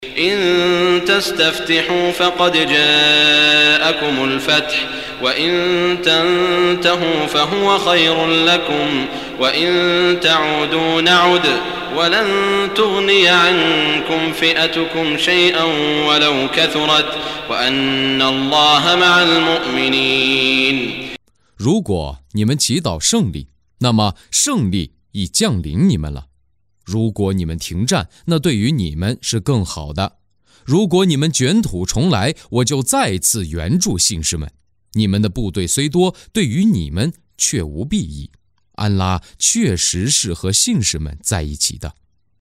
中文语音诵读的《古兰经》第（安法里）章经文译解（按节分段），并附有诵经家沙特·舒拉伊姆的朗诵